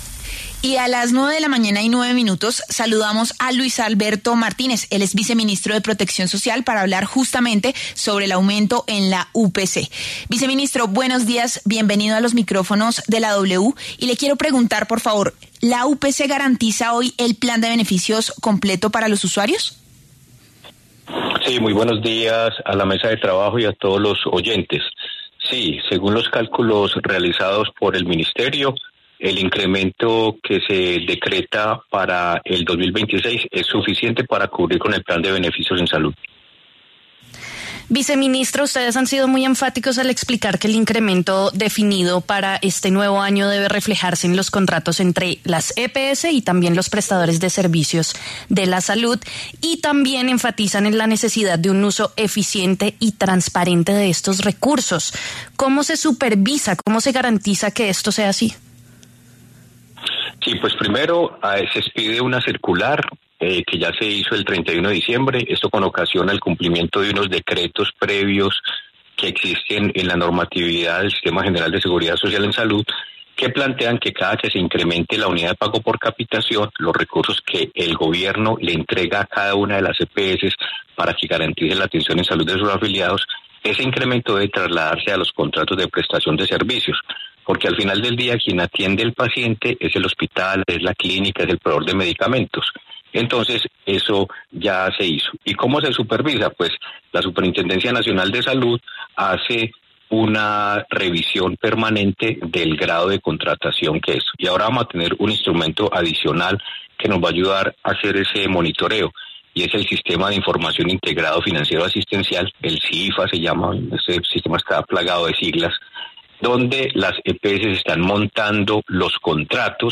El viceministro de Protección Social, Luis Alberto Martínez, afirmó en los micrófonos de La W que el incremento decretado para la Unidad de Pago por Capitación (UPC) en 2026 es suficiente para garantizar el plan de beneficios en salud de los usuarios del sistema, según los cálculos realizados por el Ministerio de Salud.